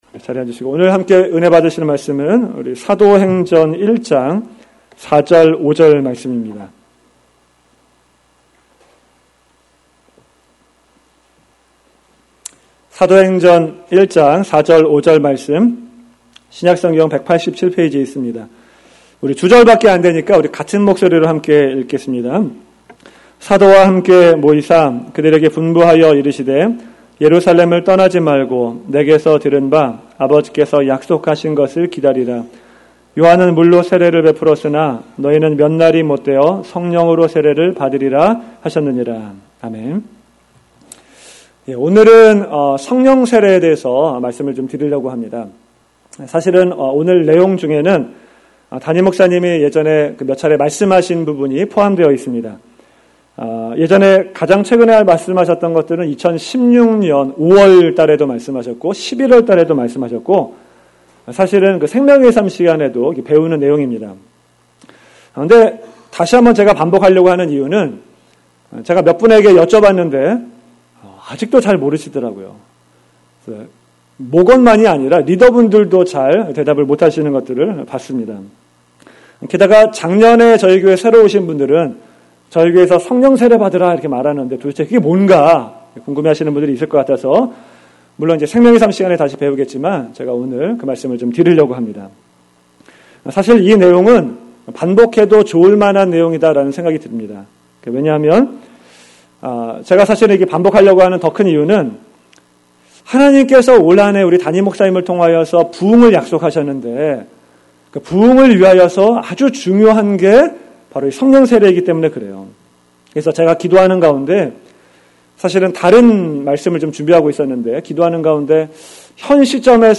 ‘Save link as’를 선택하시면 MP3 파일을 다운로드 하실 수 있습니다. 2017년 주일설교